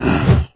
spawners_mobs_mummy_attack.3.ogg